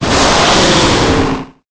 Cri_0883_EB.ogg